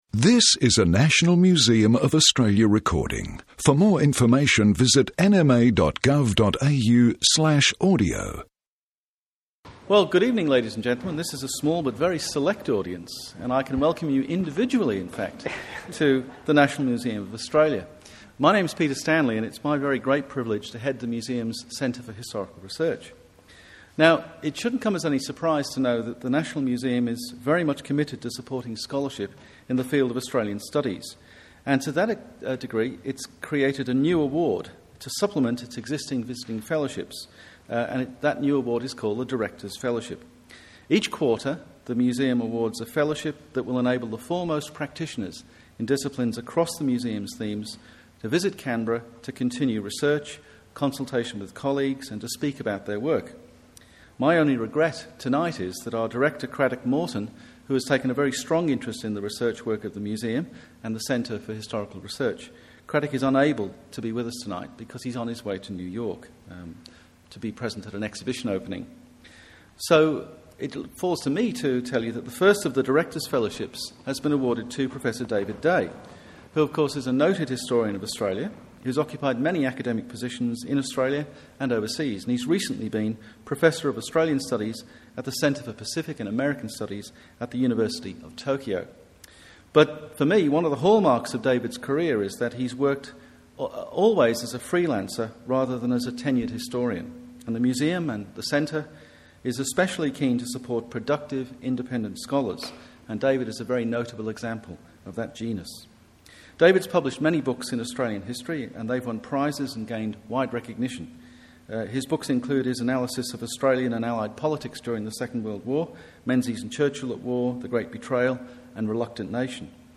Historical interpretation series 25 Oct 2007